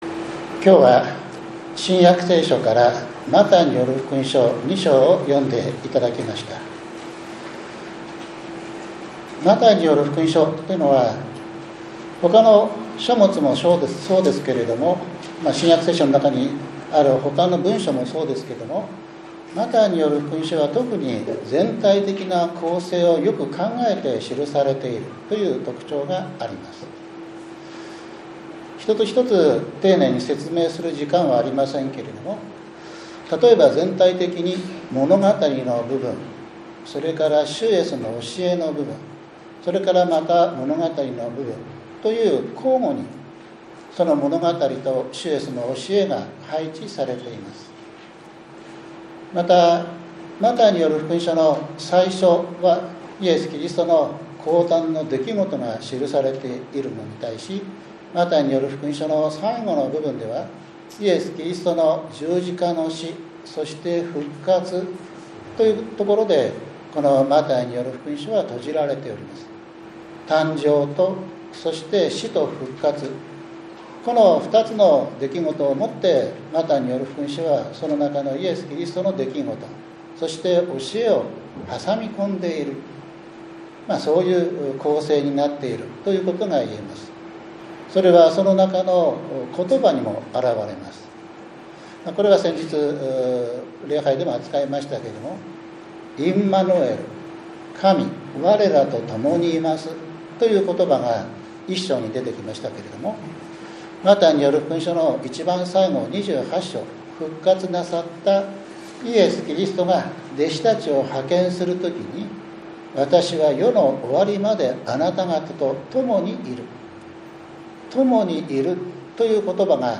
１２月２２日（日）クリスマス礼拝 エゼキエル書３４章１１節～１６節 マタイによる福音書２章１節～１２節